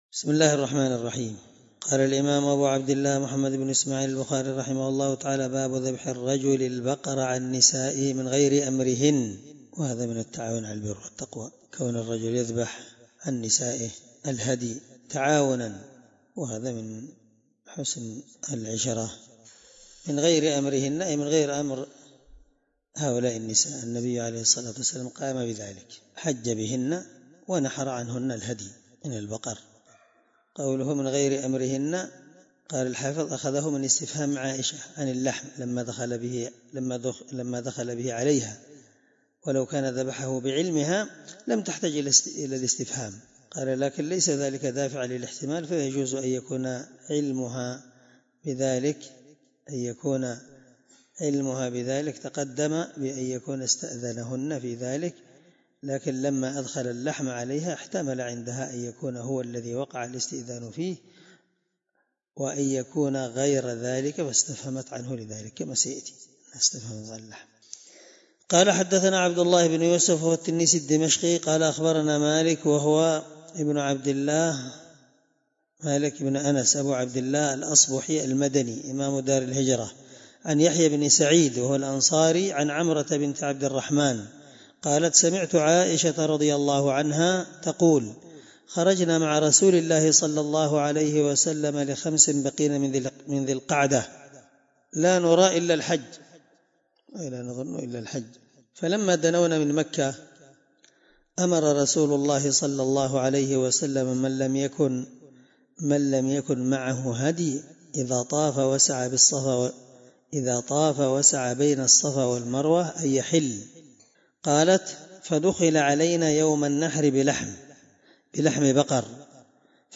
الدرس76 من شرح كتاب الحج حديث رقم(1709 )من صحيح البخاري